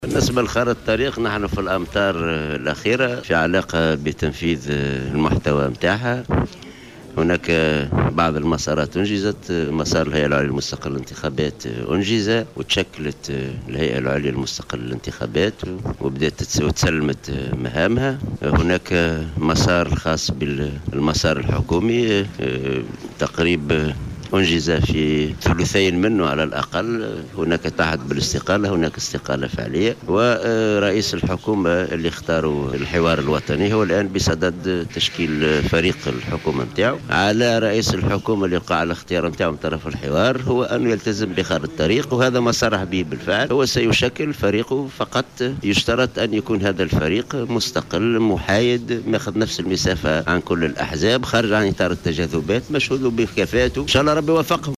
Le SG de l'UGTT a déclaré aujourd'hui au micro de Jawhara Fm que le prochain chef de gouvernement, Mehdi Jomâa, doit former un gouvernement de compétences nationales, indépendantes et apolitiques.